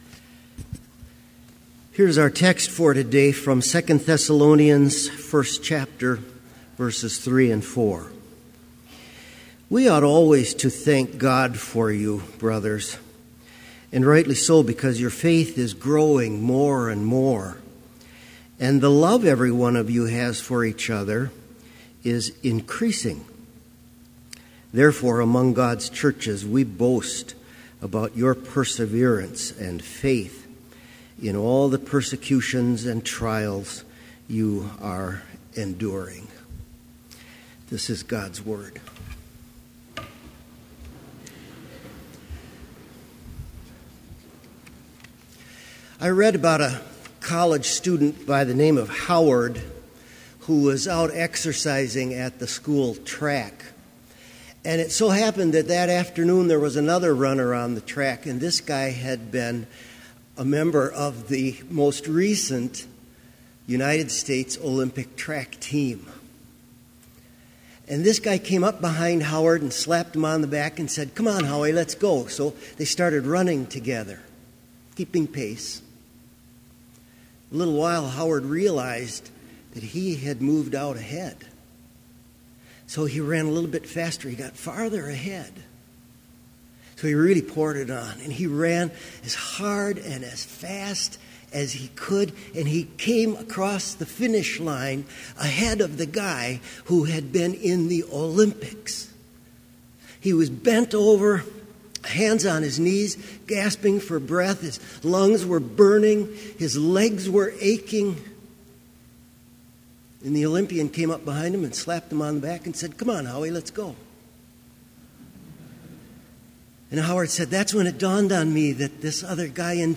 Complete Service
• Prelude
• Devotion
• Postlude
This Chapel Service was held in Trinity Chapel at Bethany Lutheran College on Wednesday, December 9, 2015, at 10 a.m. Page and hymn numbers are from the Evangelical Lutheran Hymnary.